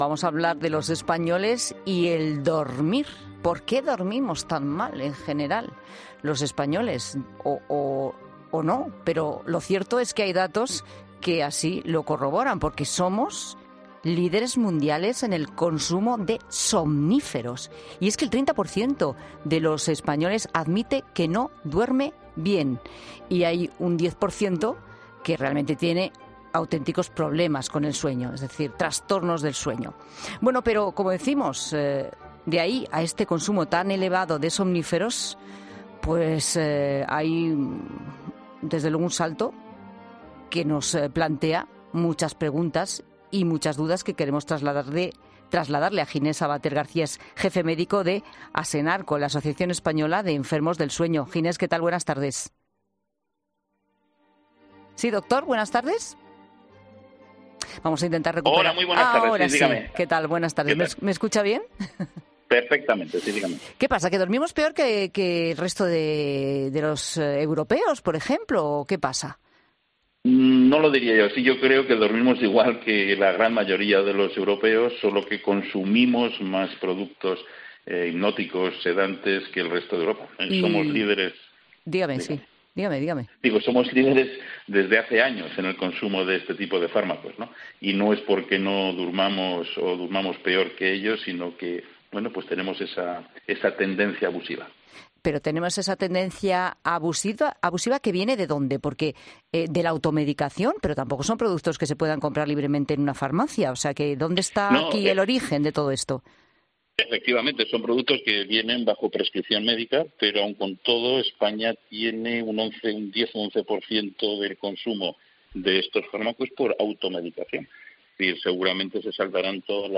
Un doctor experto en problemas de sueño ha explicado en 'La Tarde de COPE' que pese a que los españoles no dormimos peor que en otros países,...